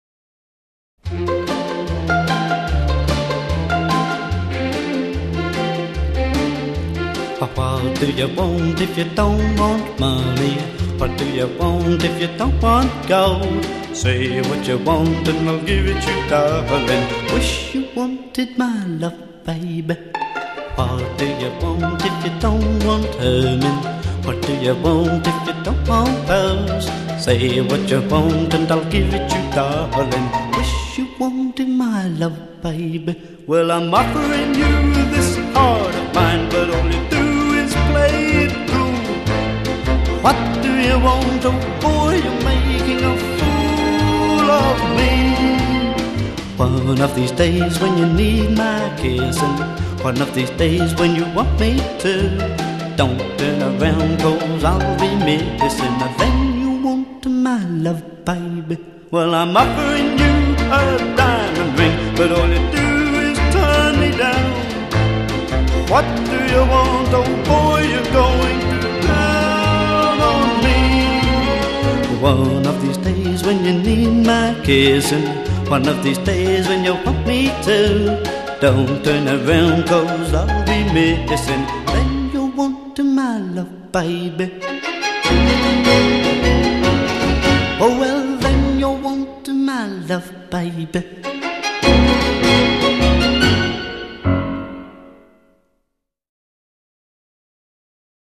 vocals
guitar
piano